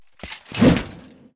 tank_attk2.wav